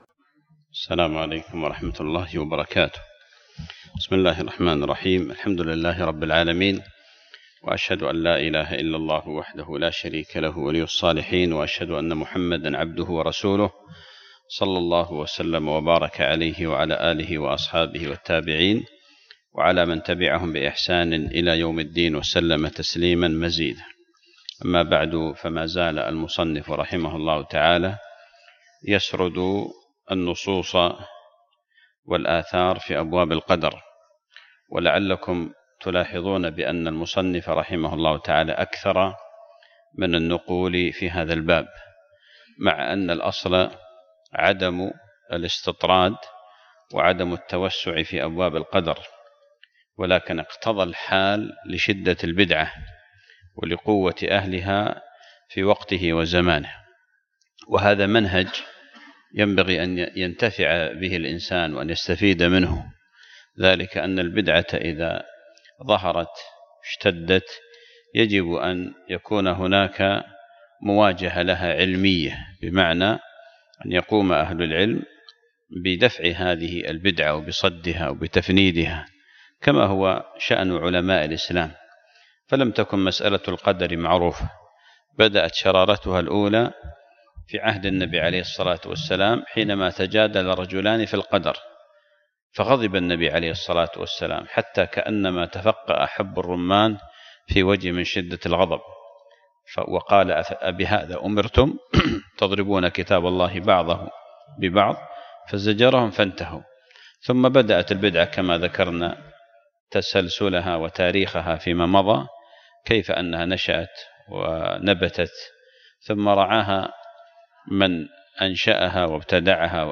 الدرس التاسع عشر